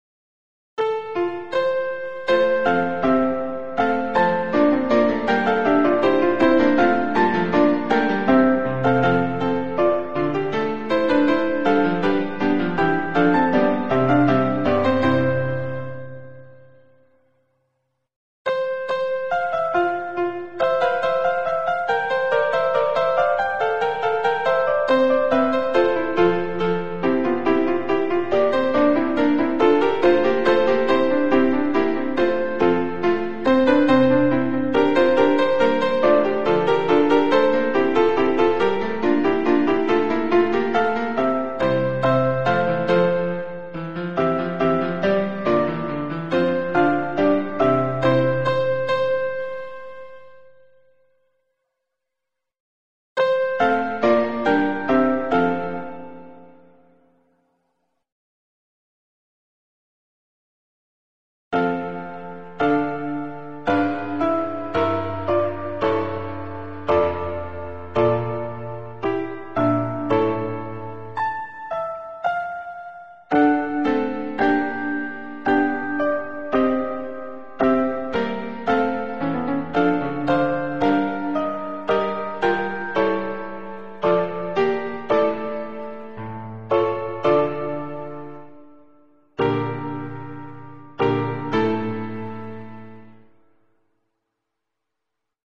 MIDI
Sans paroles
Voix + chœur en sourdine